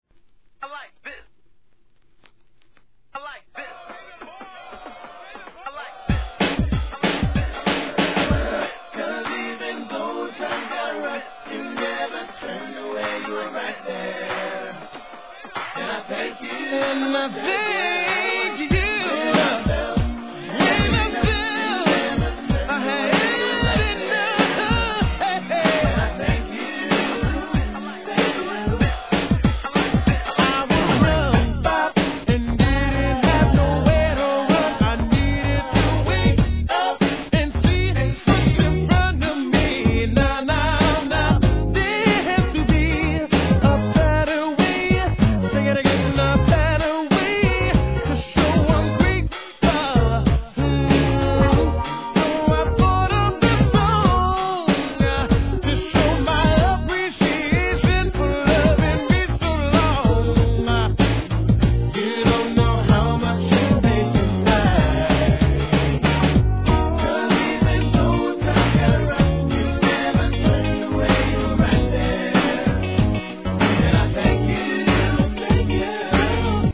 HIP HOP/R&B
素晴らしいGROOVE感溢れるコーラス・ワークがさすが!!